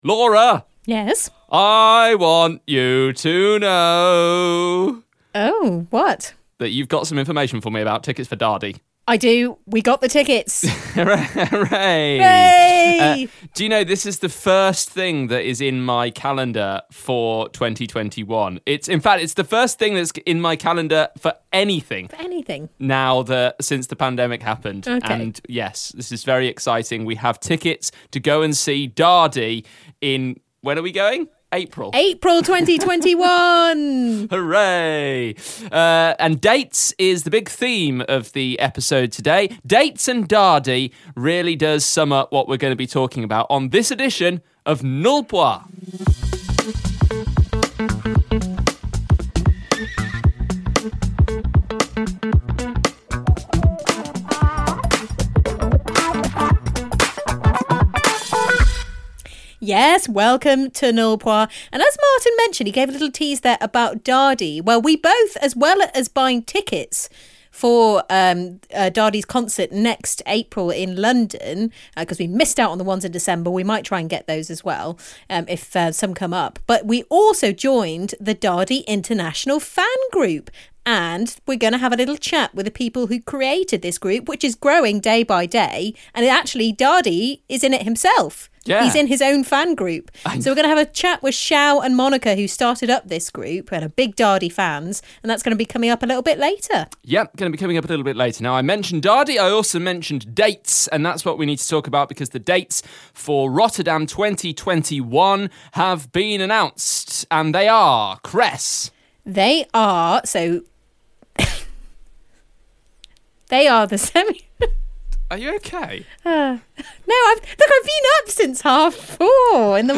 chat to the people behind the Daði Freyr International Fan Group. There's also Eurovision news as the dates for the 2021 contest have been announced.